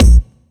GS Phat Kicks 022.wav